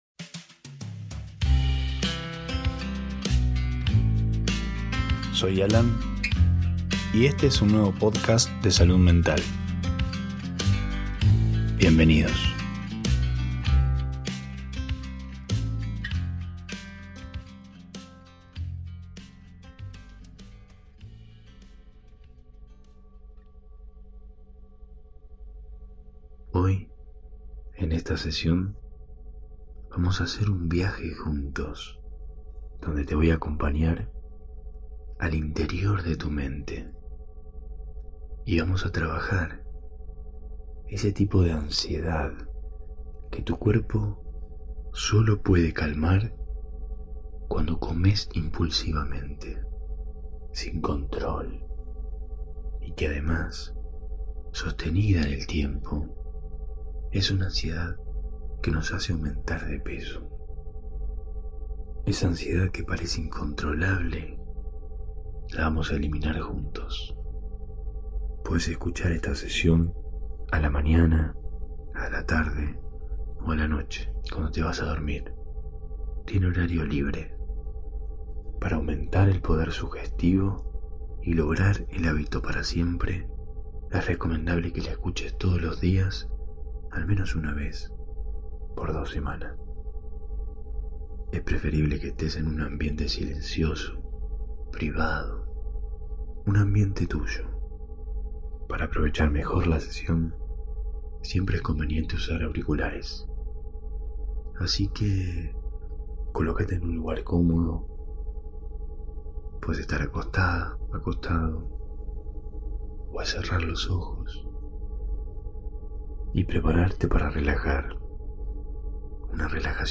Sesión de autohipnosis guiada para calmar la ansiedad oral y sumar una herramienta más para bajar de peso.